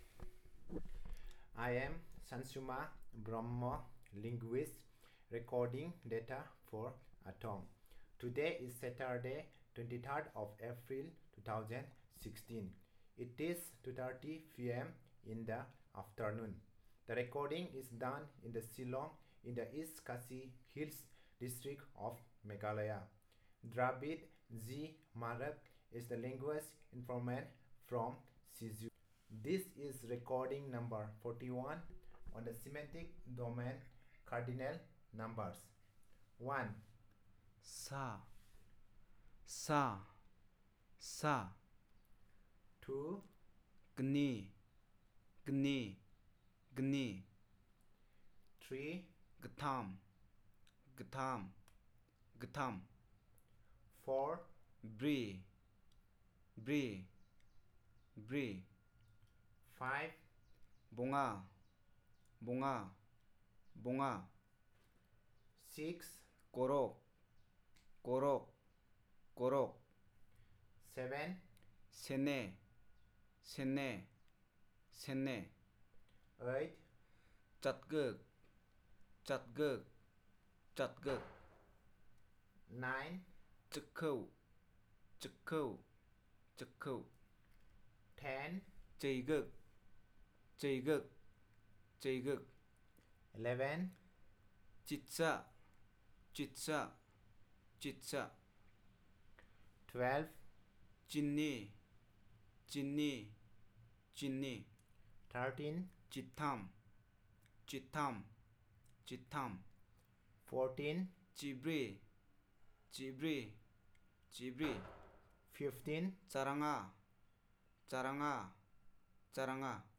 Elicitation of words about cardinal numbers